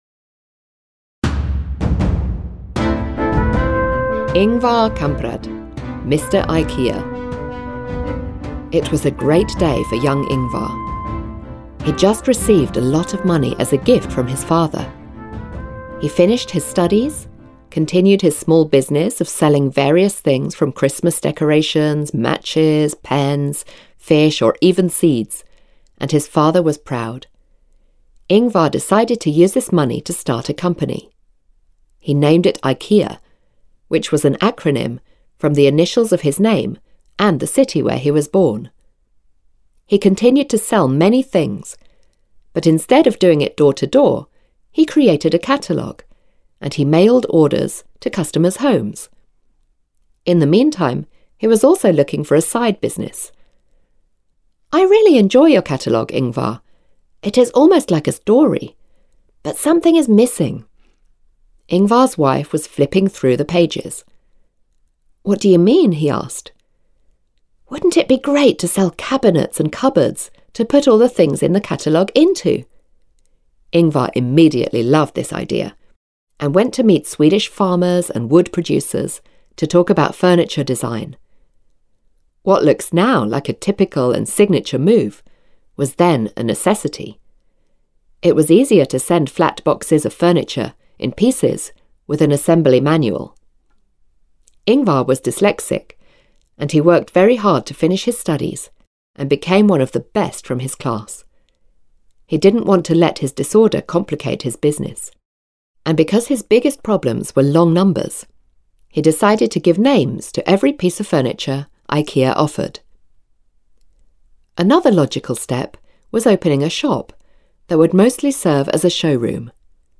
Visionaries - Men Who Changed the World B1/B2 audiokniha
Ukázka z knihy